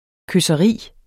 Udtale [ køsʌˈʁiˀ ]